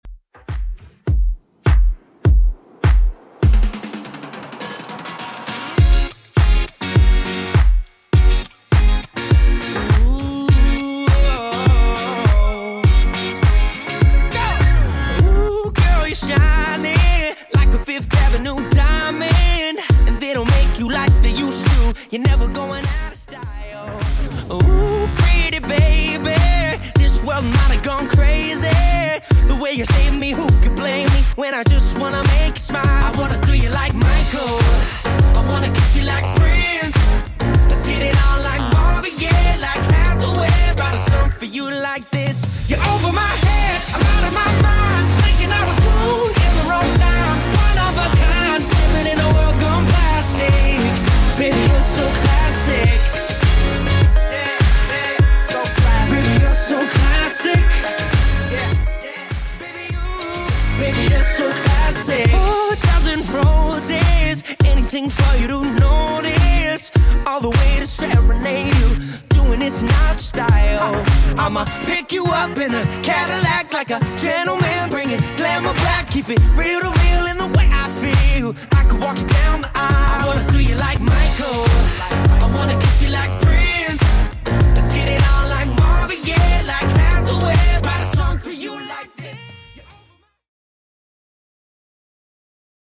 Genre: 90's
BPM: 138